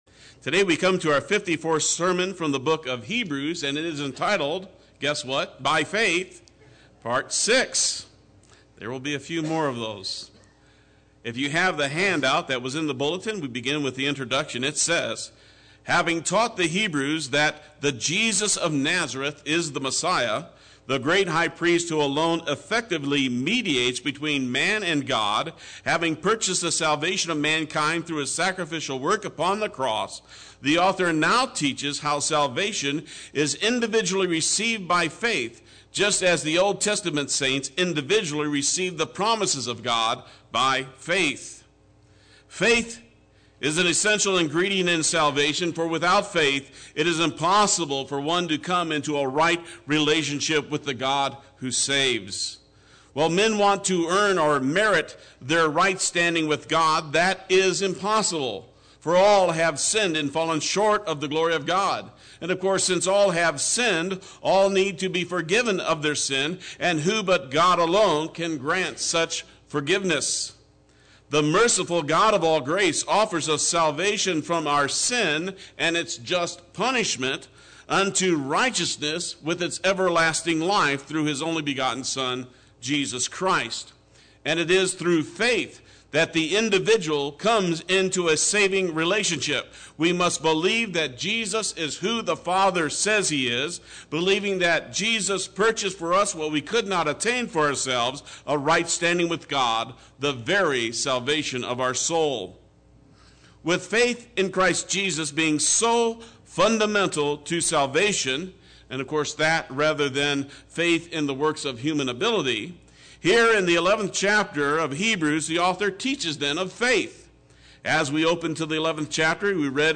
Play Sermon Get HCF Teaching Automatically.
Part 6 Sunday Worship